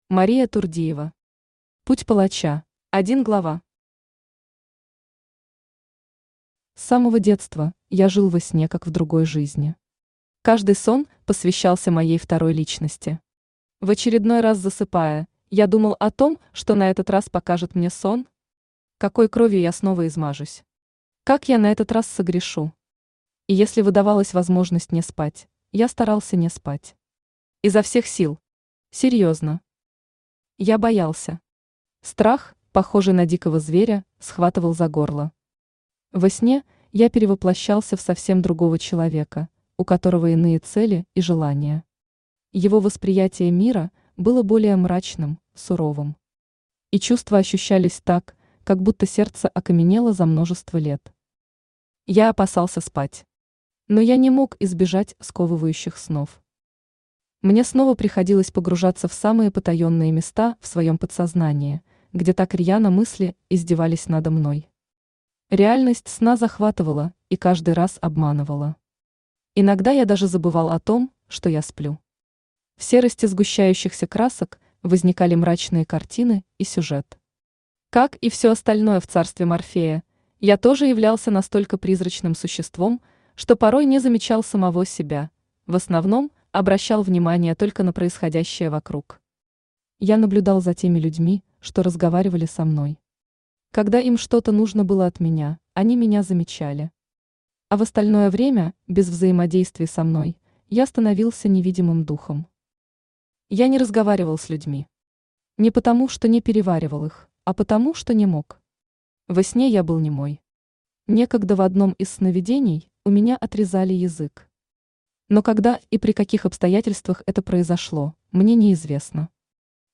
Aудиокнига Путь палача Автор Мария Турдиева Читает аудиокнигу Авточтец ЛитРес.